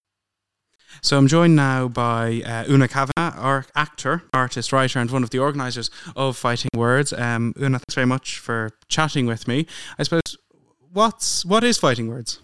Lag/Glitchy noise on recording
I was doing a series of interviews yesterday through audacity with a RodeCaster Pro. Unfortunately, the recordings make a laggy noise every few seconds (as in the example attached).